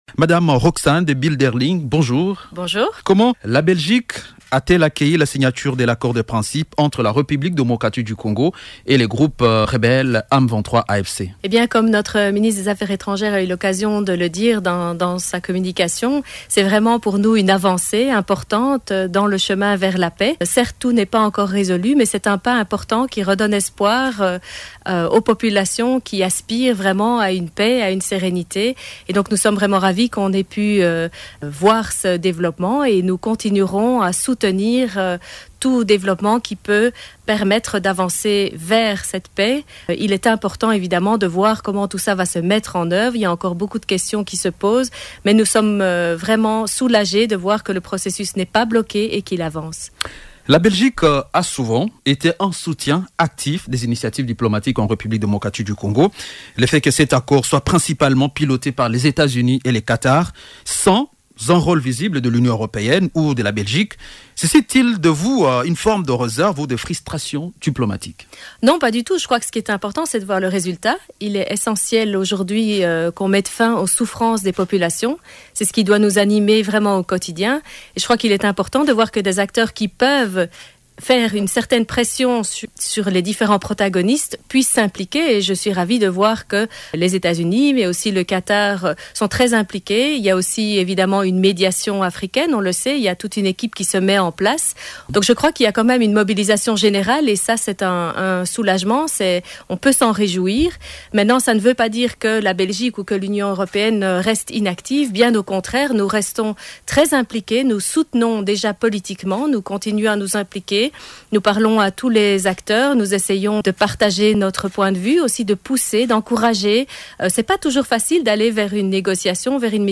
Elle s'est exprimée le mercredi 23 juillet lors d'un entretien exclusif avec Radio Okapi, évoquant les initiatives diplomatiques en cours.